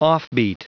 Prononciation du mot offbeat en anglais (fichier audio)
Prononciation du mot : offbeat